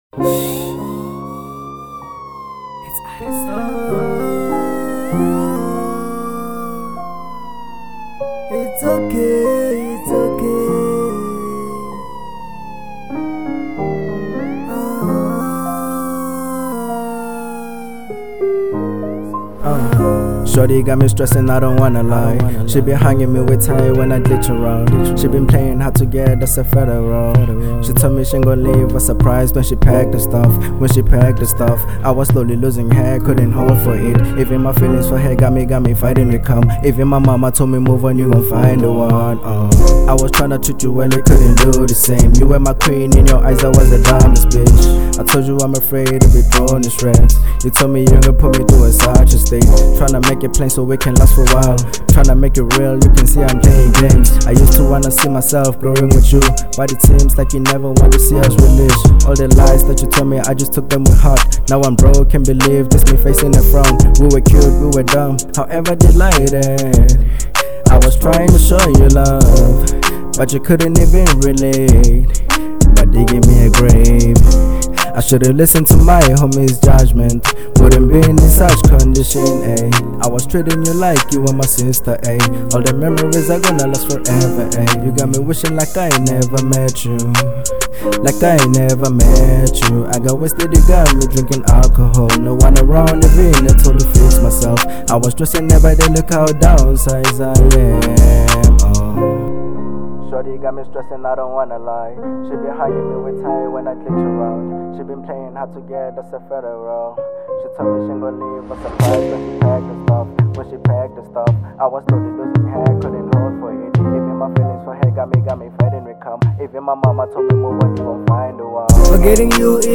02:58 Genre : Hip Hop Size